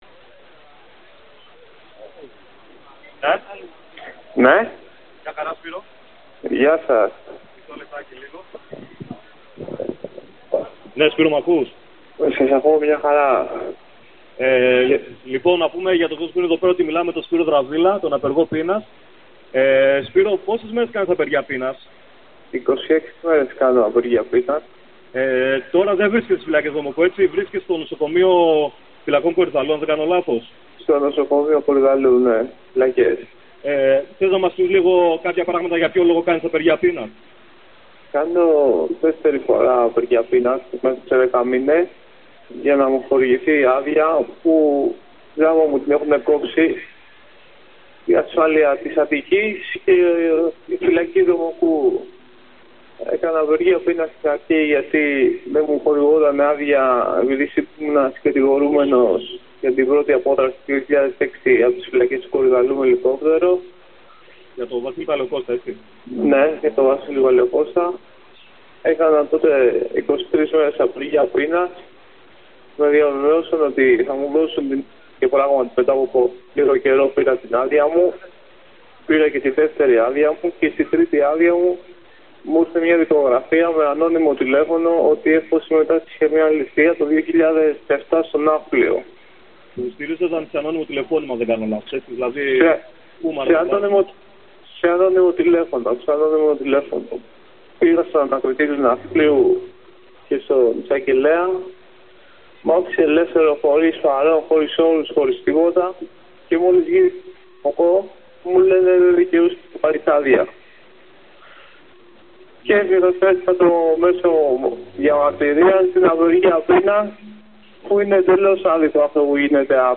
μικροφωνική αλληλεγγύης
τηλεφωνική παρέμβαση